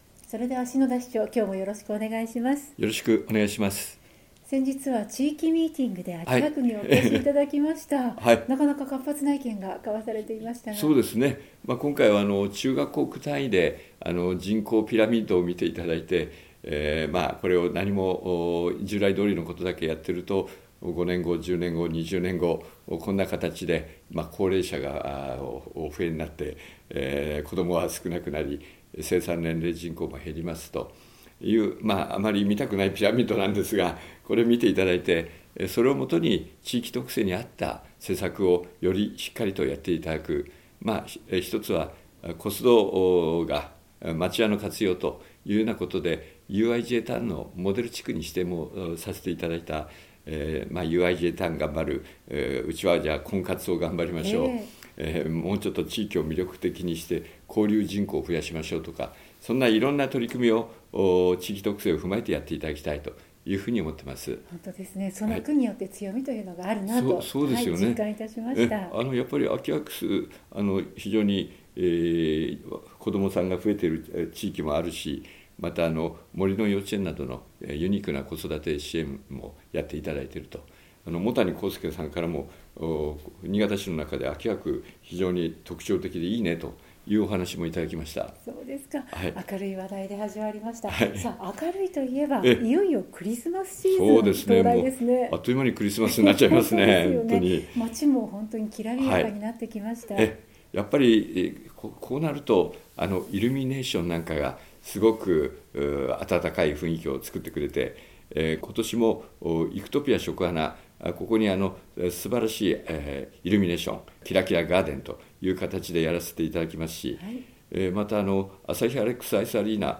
篠田市長の青空トーク | RADIOCHAT76.1MHz | ページ 12